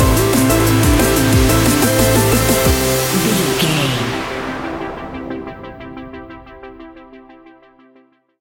Ionian/Major
Fast
uplifting
futuristic
driving
energetic
drum machine
synthesiser
electronic
synth leads
synth bass